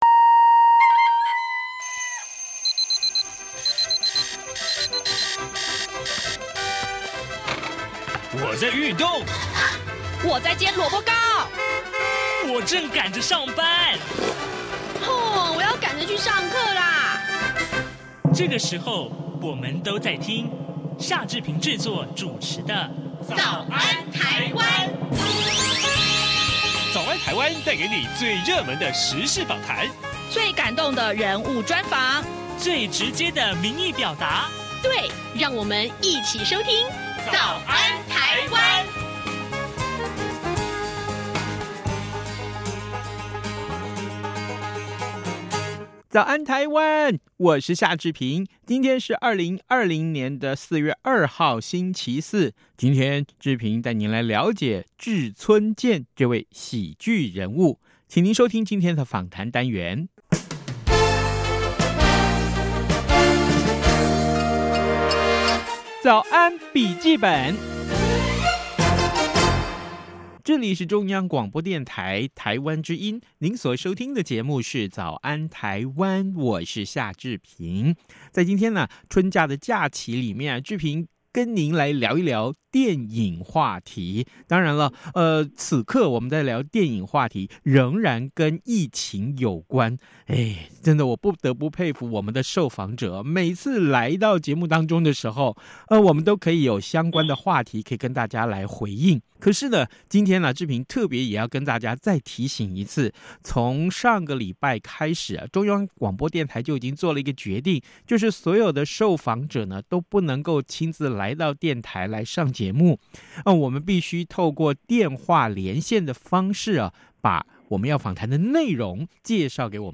專訪影評人